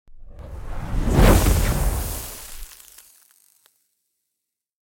دانلود آهنگ آتش 20 از افکت صوتی طبیعت و محیط
دانلود صدای آتش 20 از ساعد نیوز با لینک مستقیم و کیفیت بالا
برچسب: دانلود آهنگ های افکت صوتی طبیعت و محیط دانلود آلبوم صدای شعله های آتش از افکت صوتی طبیعت و محیط